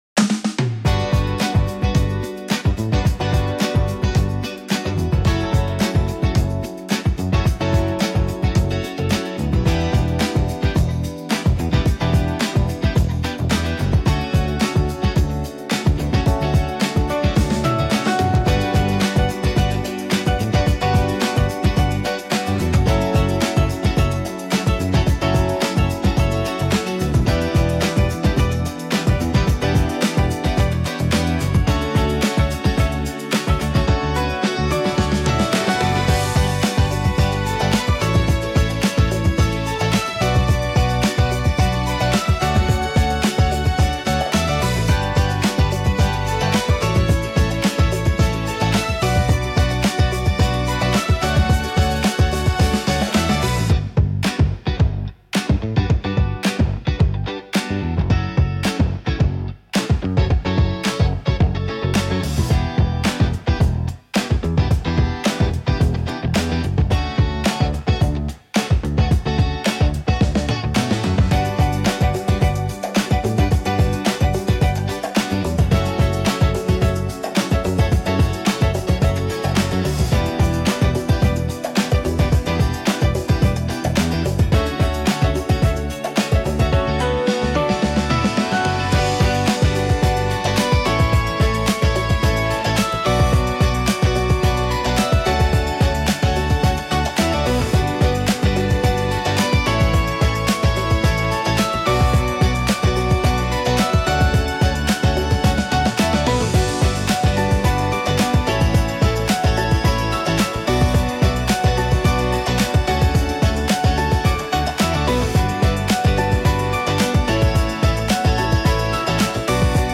ファンクポップ・インストゥルメンタル・ボーカル無し
アップテンポ 明るい